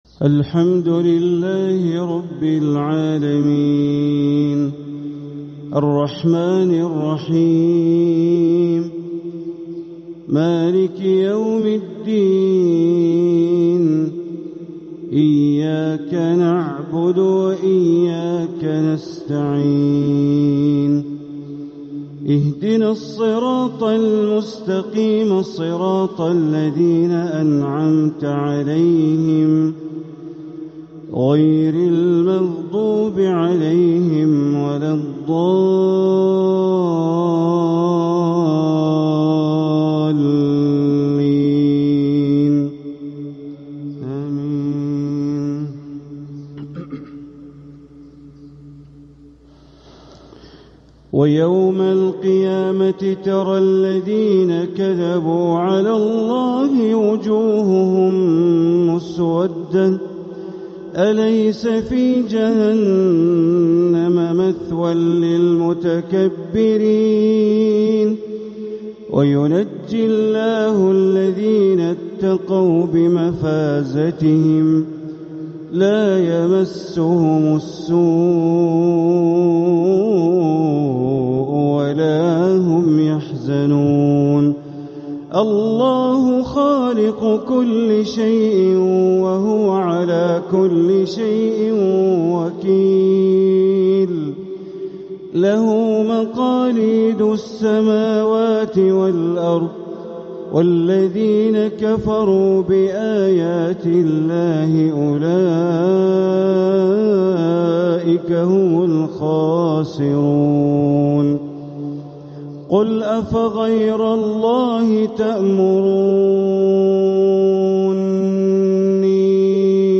سلسلة اللآلئ الأصيلة لتلاوات الشيخ بندر بليلة لتلاوات شهر جمادى الآخرة (الحلقة الثانية والثمانون ) > سلسلة اللآلئ الأصيلة لتلاوات الشيخ بندر بليلة > المزيد - تلاوات بندر بليلة